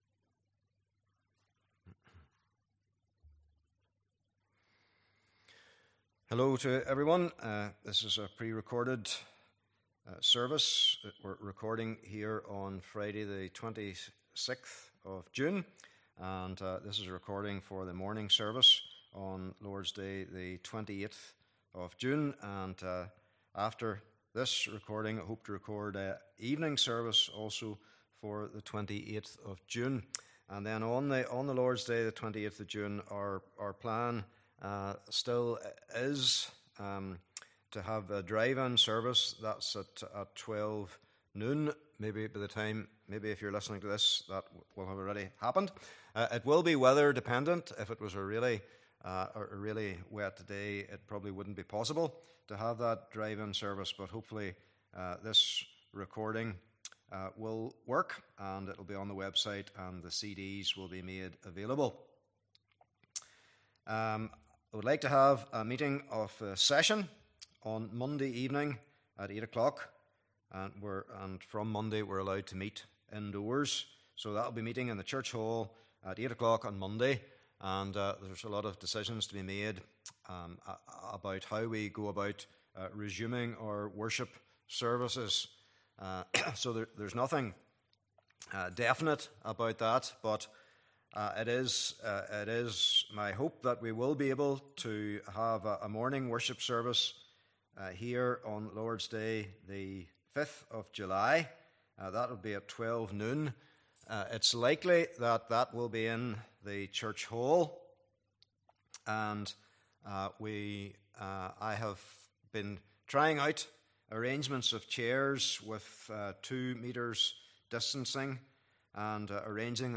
27 Service Type: Morning Service Bible Text